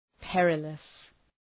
Προφορά
{‘perıləs}